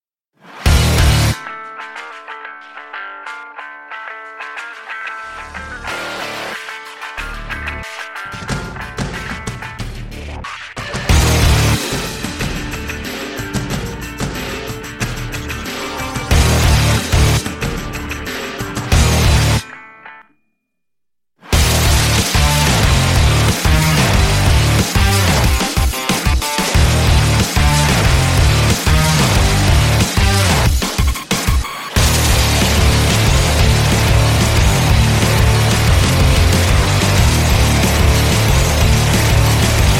громкие
мощные
nu metal
качающие
Electronic Rock
Industrial metal
Synth Rock
electro industrial